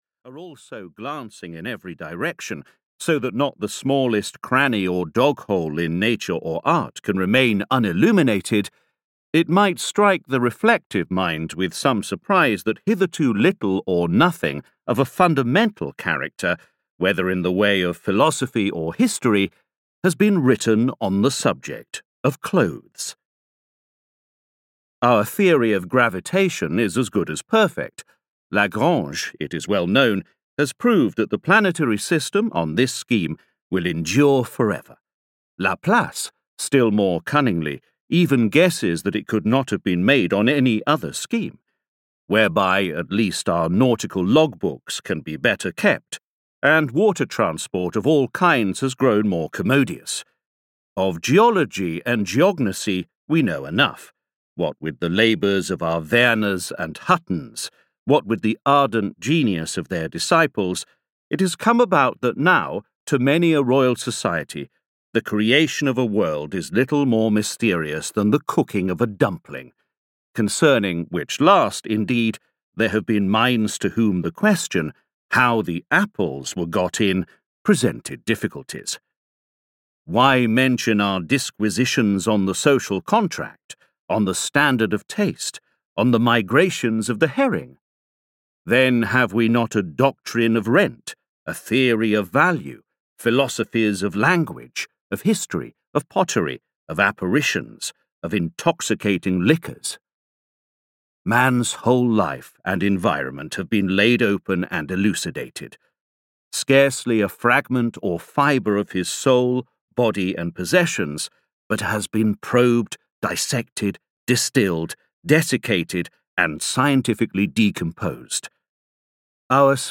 Sartor Resartus (EN) audiokniha
Ukázka z knihy